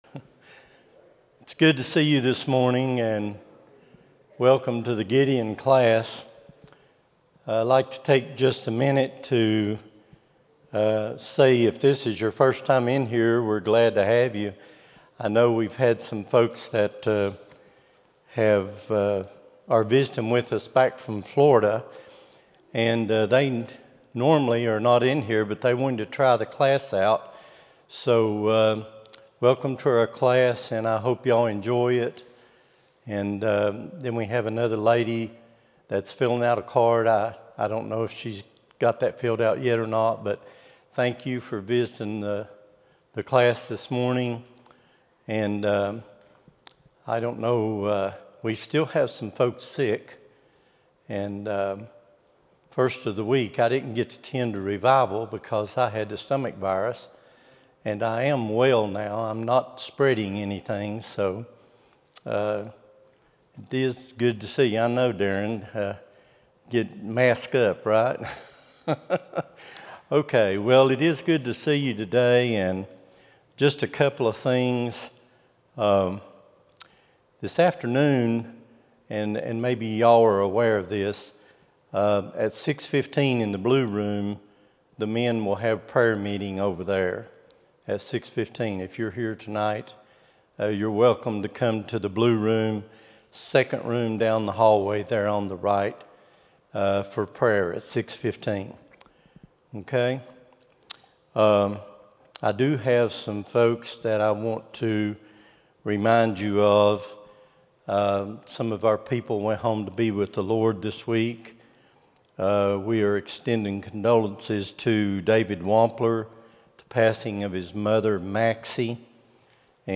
04-19-26 Sunday School | Buffalo Ridge Baptist Church
Sunday School lesson
at Buffalo Ridge Baptist Church in Gray, Tn.